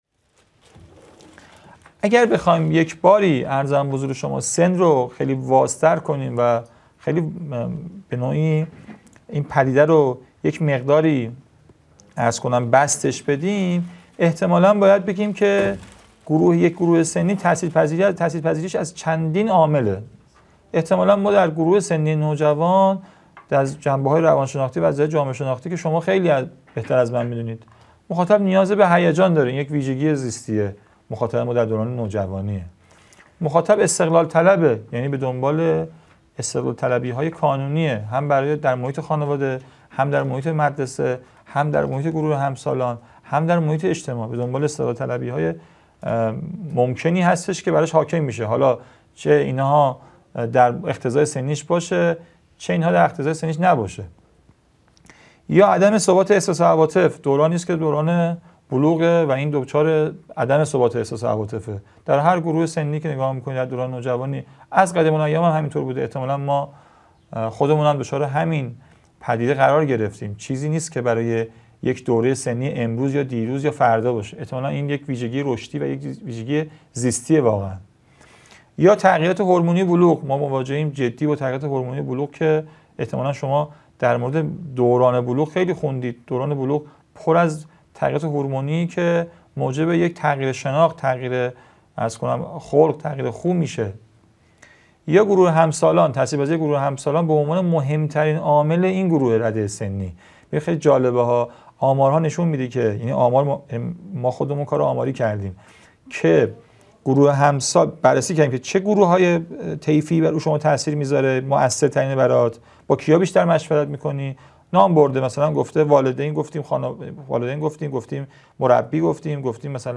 گزیده پنجم از دومین سلسله نشست‌ های هیأت و نوجوانان - با موضوعیت نوجوان در ایران
قم - اردبیهشت ماه 1402